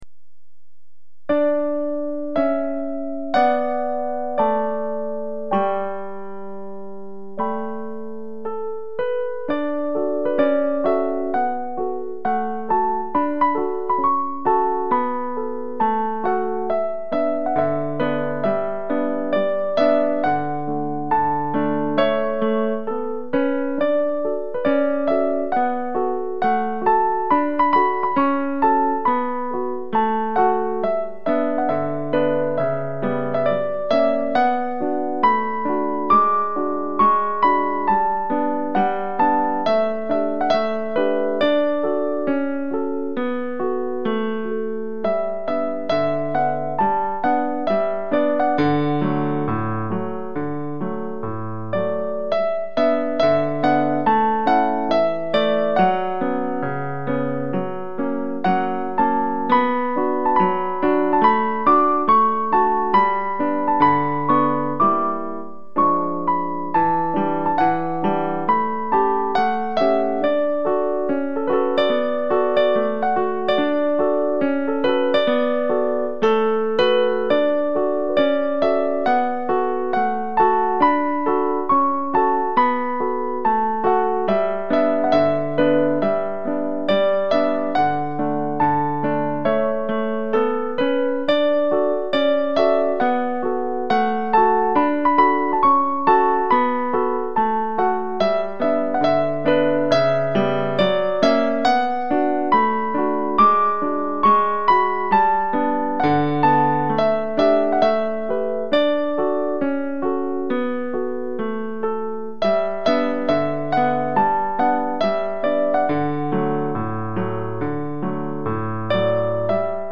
Video games music for the piano